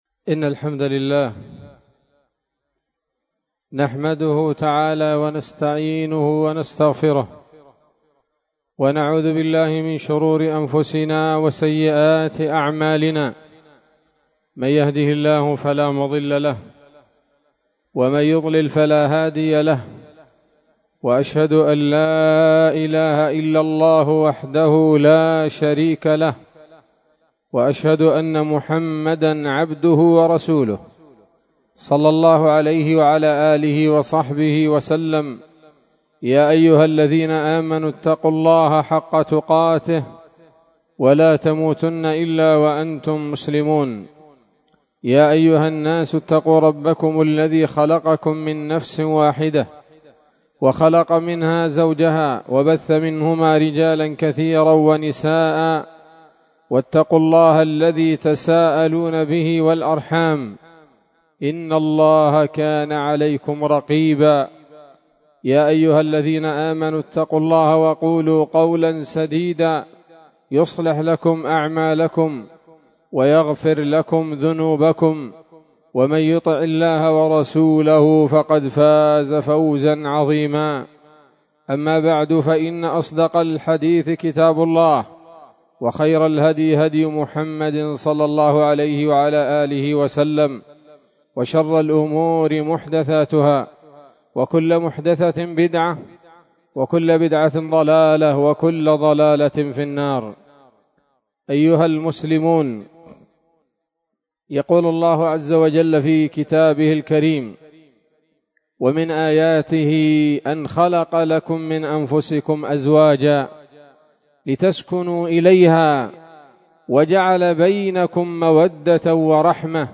خطبة جمعة بعنوان: (( السراج الوهاج في بيان أسباب نجاح الزواج [1] )) 21 صفر 1447 هـ، دار الحديث السلفية بصلاح الدين